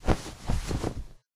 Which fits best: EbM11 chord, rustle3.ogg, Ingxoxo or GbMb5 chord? rustle3.ogg